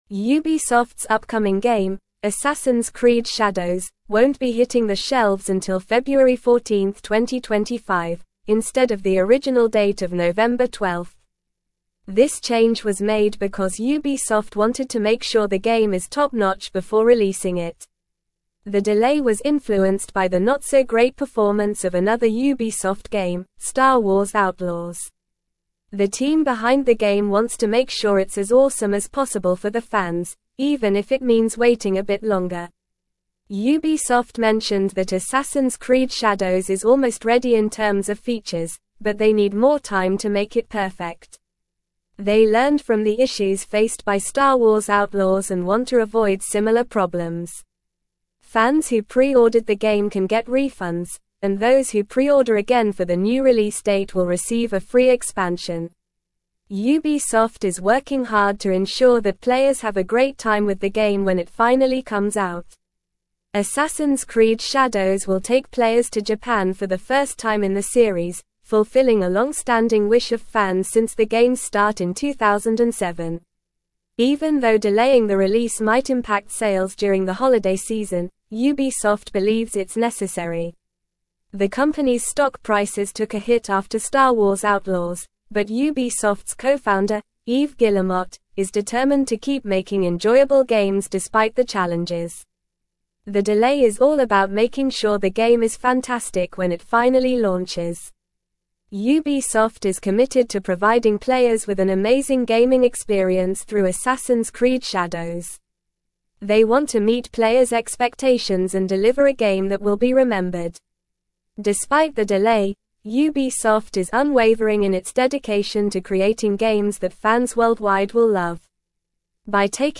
Normal
English-Newsroom-Upper-Intermediate-NORMAL-Reading-Ubisoft-Delays-Assassins-Creed-Shadows-Release-to-2025.mp3